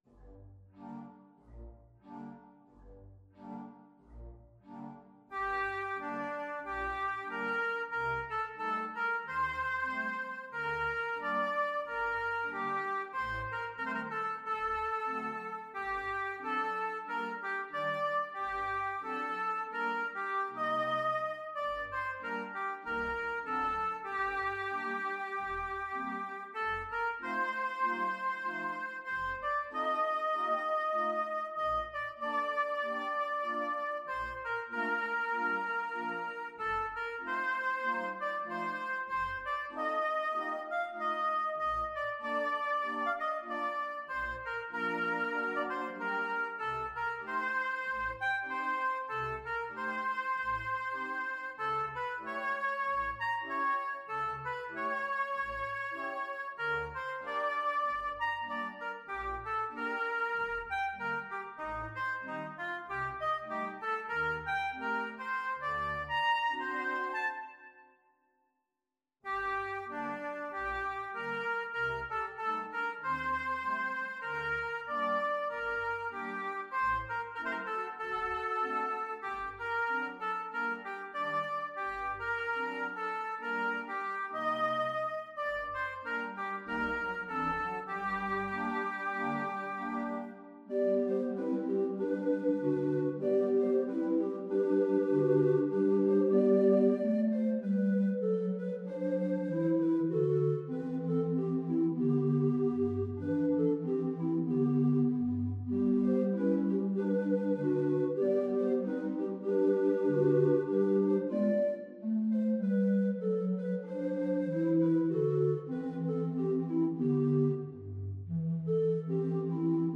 Organ  (View more Intermediate Organ Music)
Classical (View more Classical Organ Music)
(MIDI)